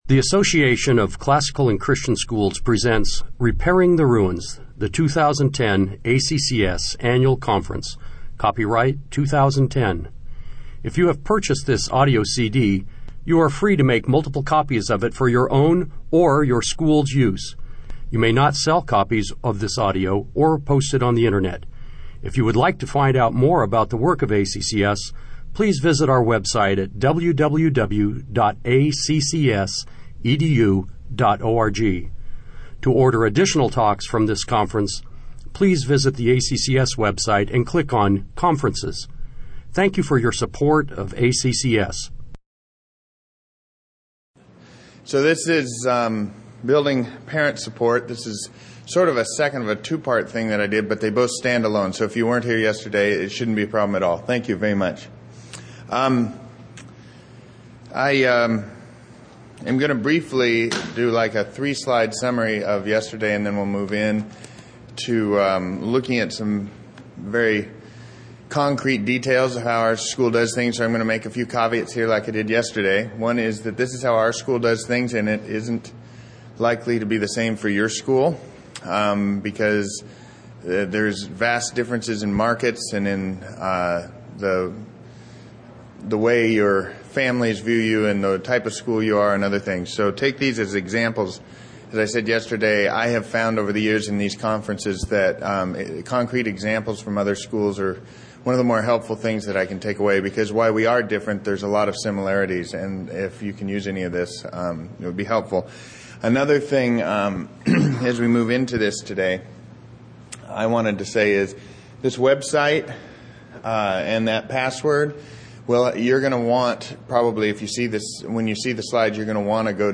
2010 Workshop Talk | 1:04:52 | Leadership & Strategic, Marketing & Growth
The Association of Classical & Christian Schools presents Repairing the Ruins, the ACCS annual conference, copyright ACCS.